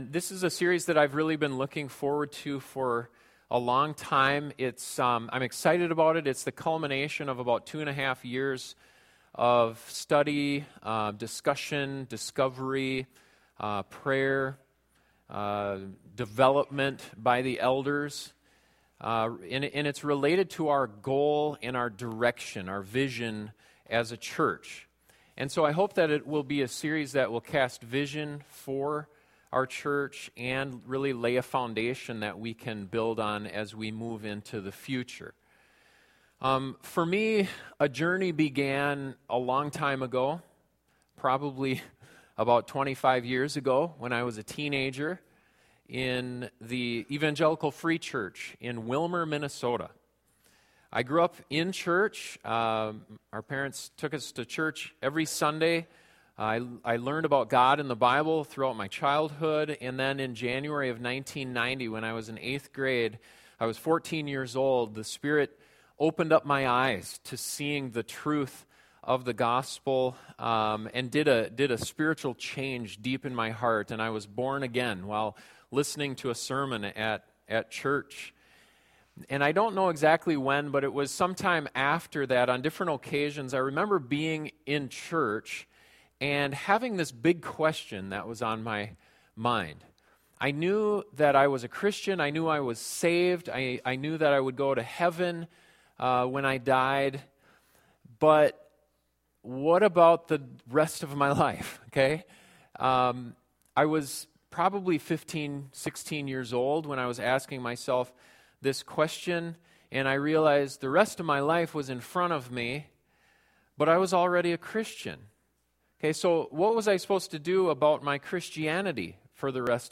This sermon answers the question: What is the goal that should unite us together as we move forward as a church family?